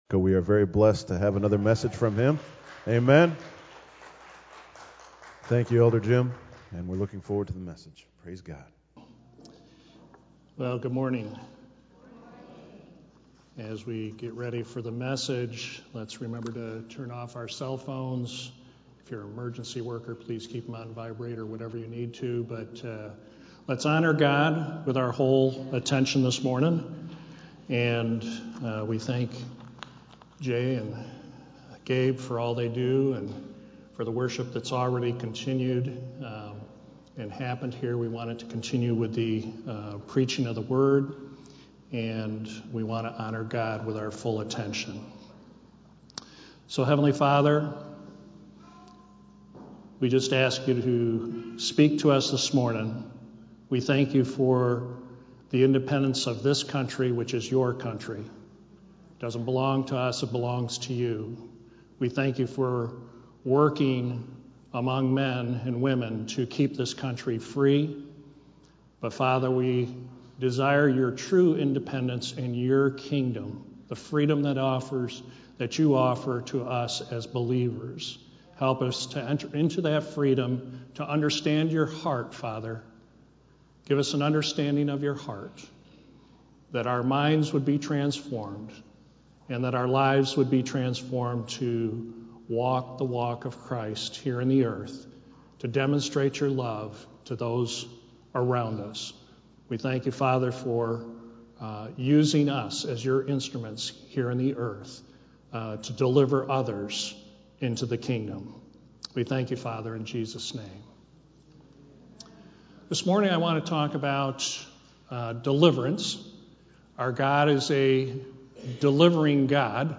Sermons Archive - River of Life Community Church